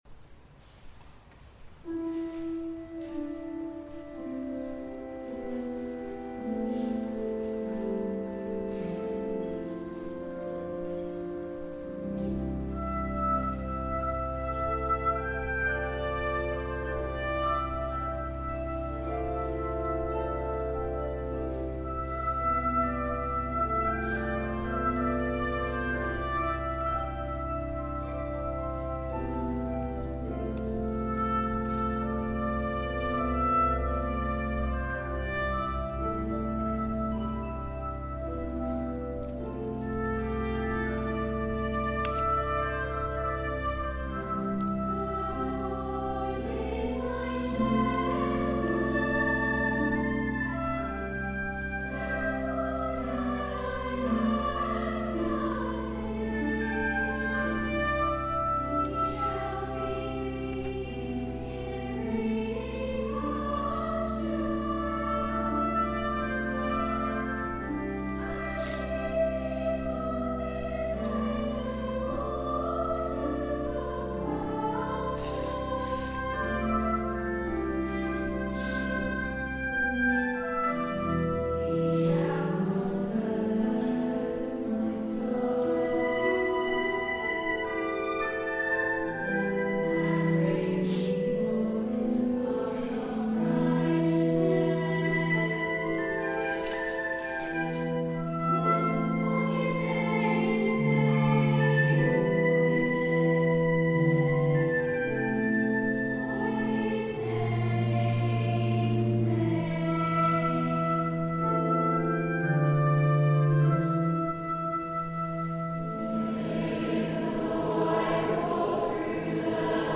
From the Requiem by John Rutter, performed at the Simon Balle Choral Concert 2017